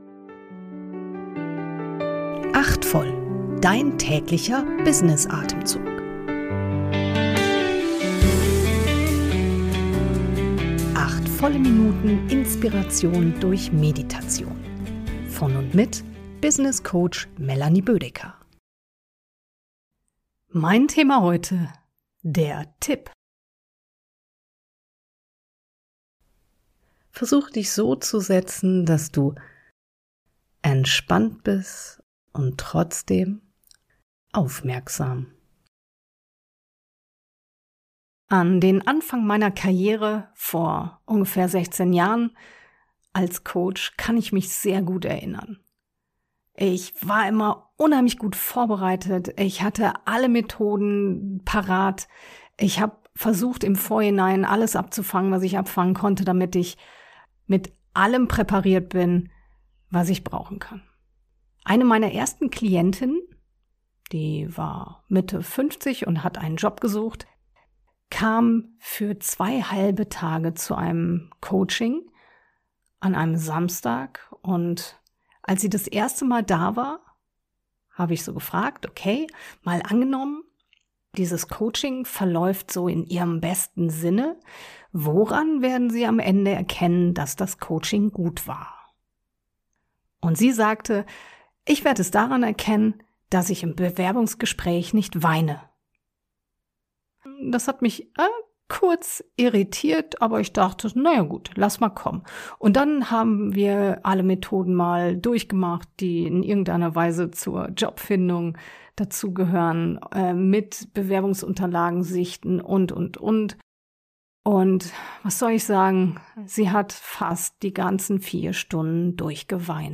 Kurz-Meditation.